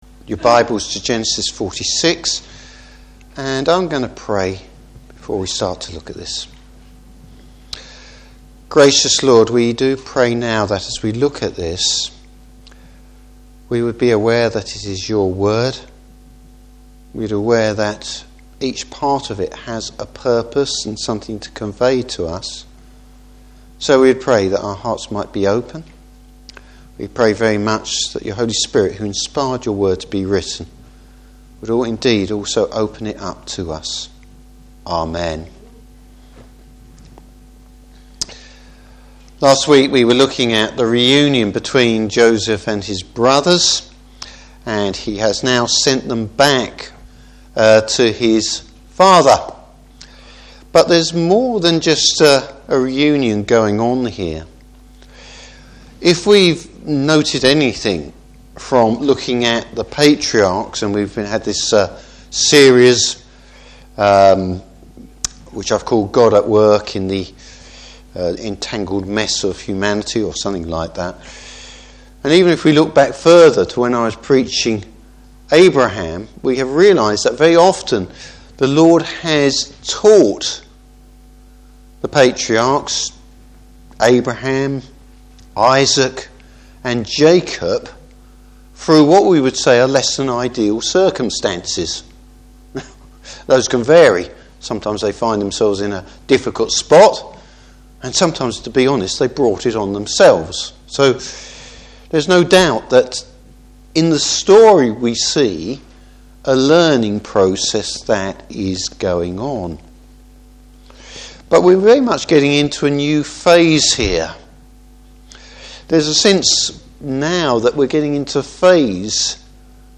Service Type: Evening Service Joseph acts as an intercessor.